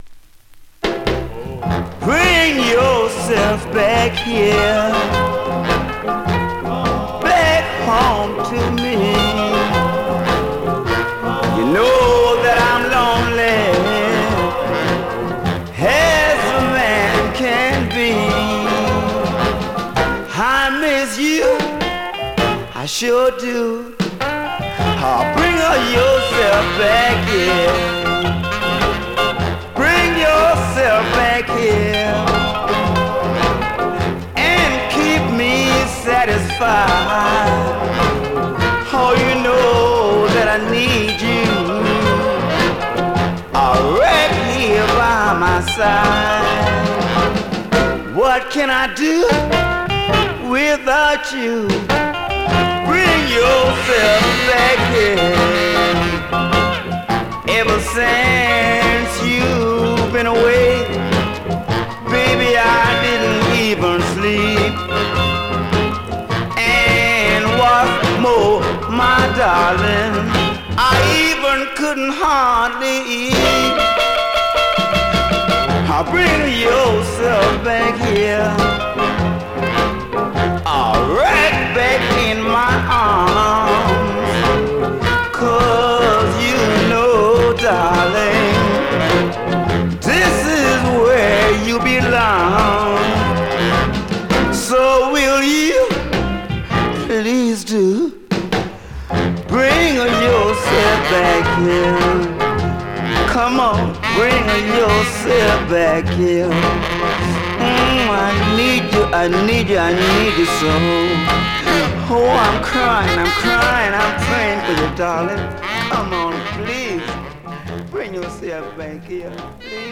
Vinyl has a few very light marks plays great .
Great classic mid-tempo Rnb / Mod dancer
R&B, MOD, POPCORN